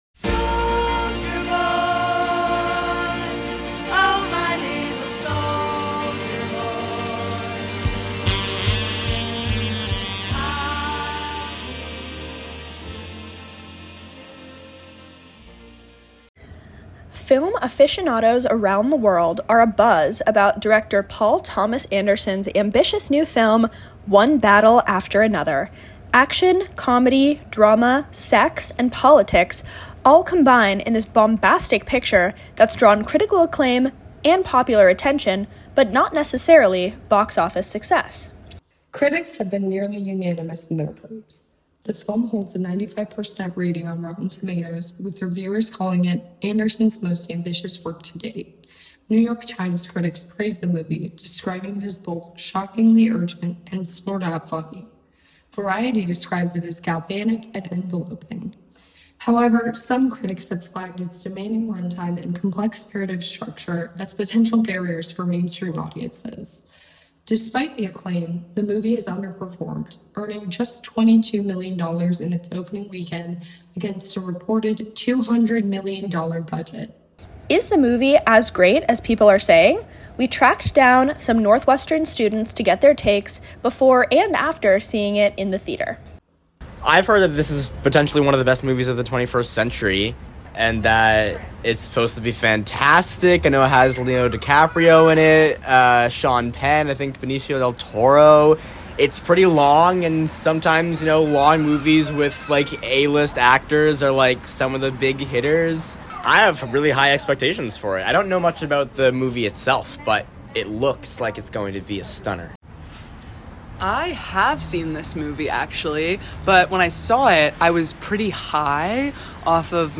Intro music: Soldier Boy by the Shirelles